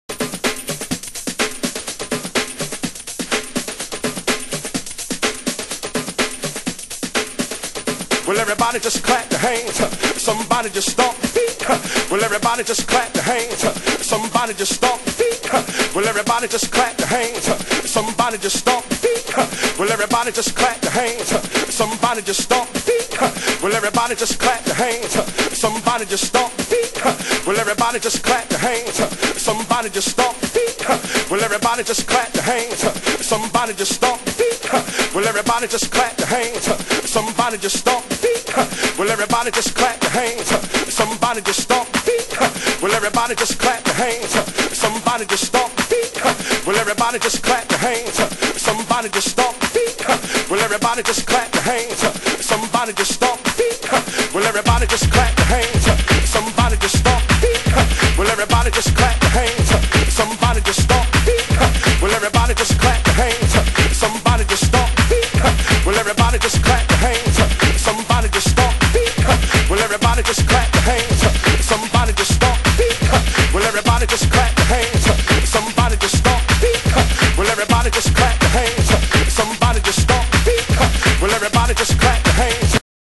盤質：小傷/少しチリパチノイズ有　　ジャケ：少しスレ有